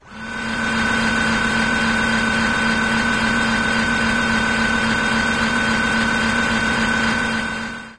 GunRev.ogg